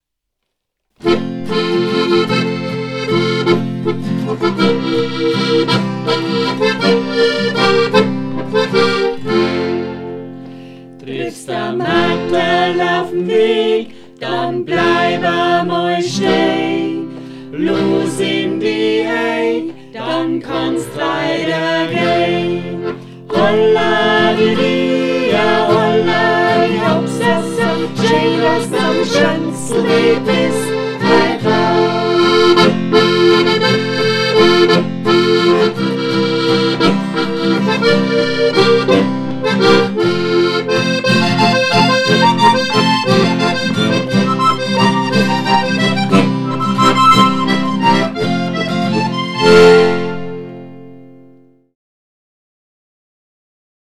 1_Gstanzl_Birgittenstatue.mp3